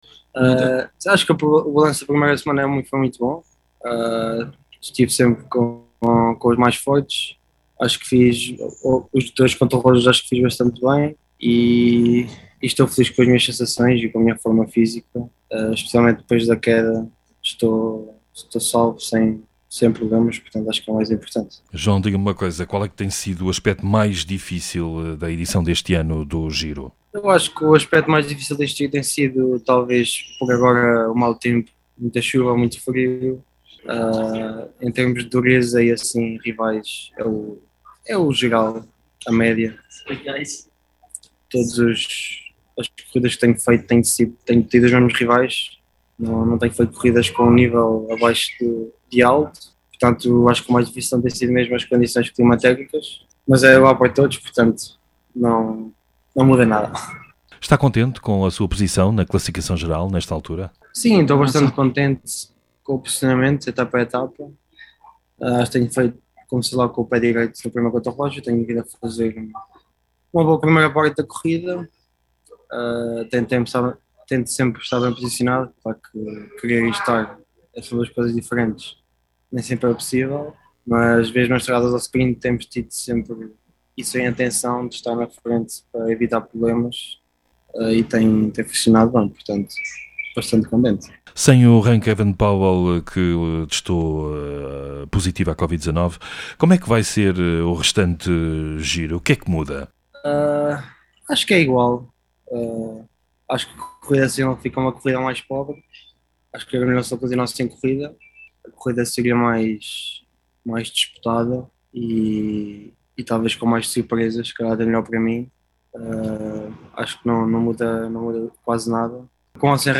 O ciclista português esteve ontem numa videoconferência e, entre outros temas, falou das suas ambições para as próximas duas semanas de competição e do abandono de Evenepoel que testou positivo à Covid-19 quando liderava a ‘Corsa Rosa’.
Entrevista difundida na emissão Tribuna Desportiva.